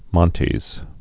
(mŏntēz)